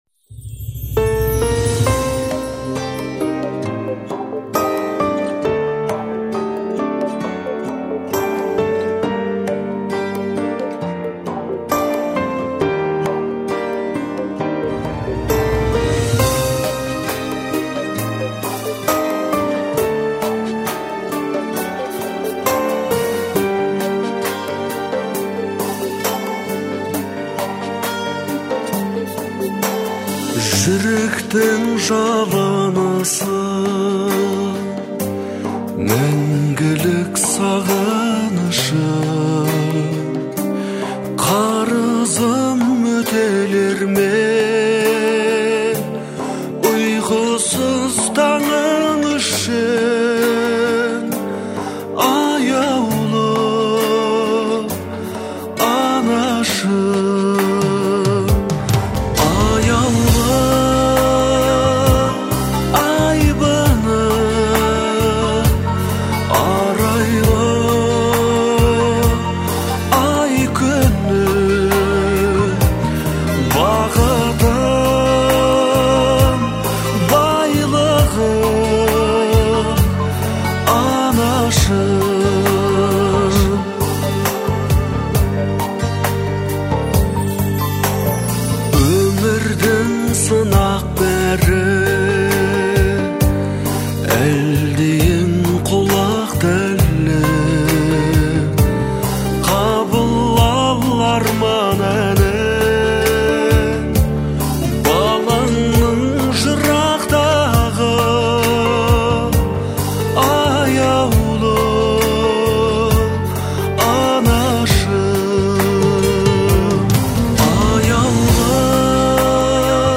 гармоничными мелодиями, создающими атмосферу тепла и уюта